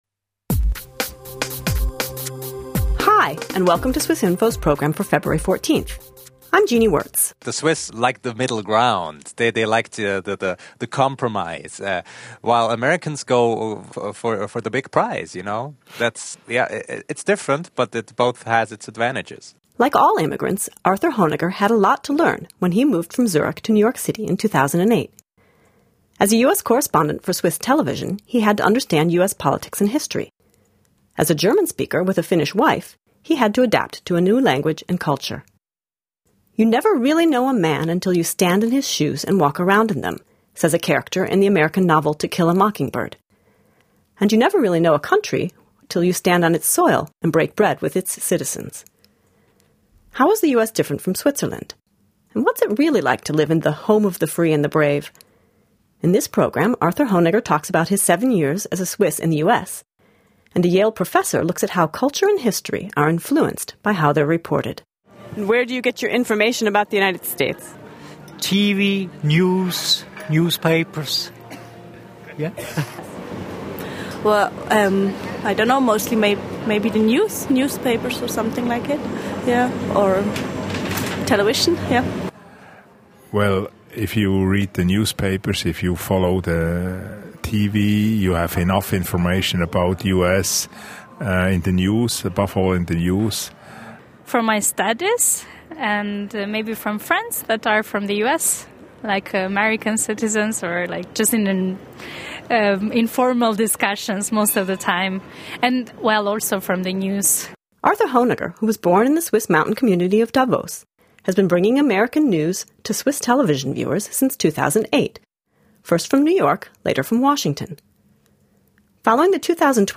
A Swiss journalist, an American sociologist and a few residents of Switzerland share their impressions of American culture and history